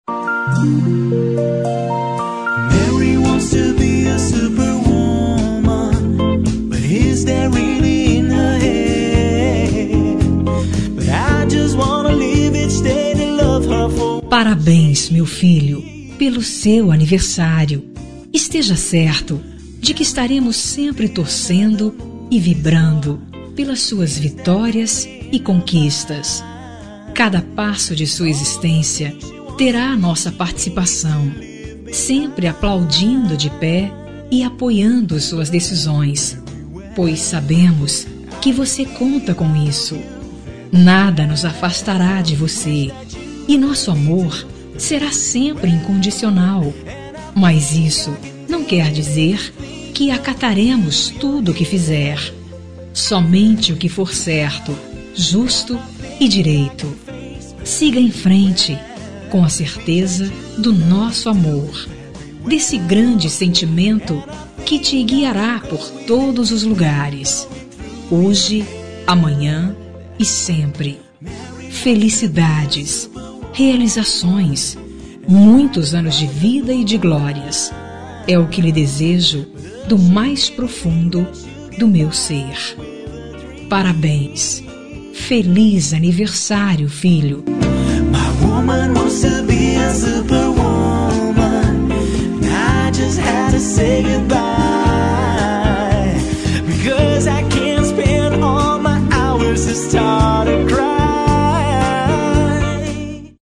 Aniversário de Filho – Voz Feminina – Cód: 5303 – Pais Enviando
5303-aniv-pais-enviando-fem.mp3